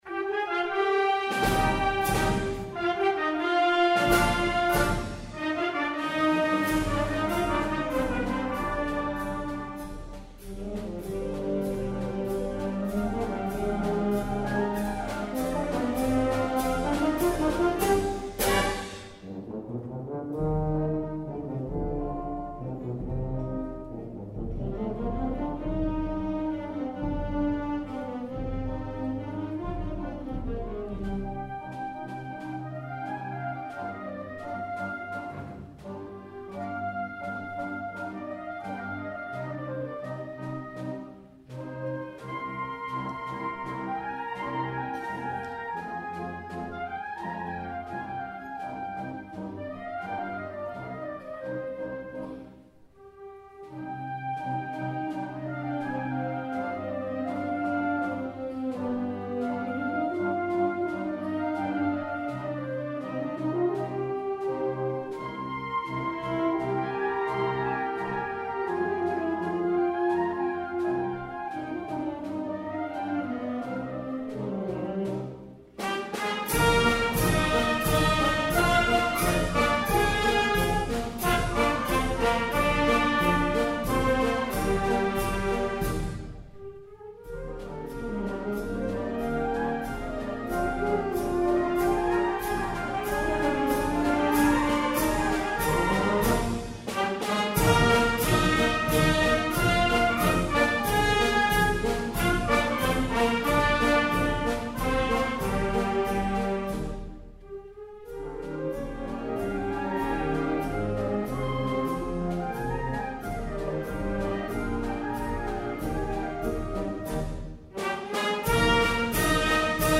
En la seva faceta de compositor, va destacar en els pasdobles, dels quals va escriure més de 50, com “Peña Vinaza”, “Llevant”, “Arturo Box”, “Foios”, i l'immortal “Ateneu Musical”, la composició que va dedicar amb tot el seu afecte a la banda del mateix nom i que interpreta amb orgull en totes les ocasions, són alguns dels més representatius del mestre de Torrent.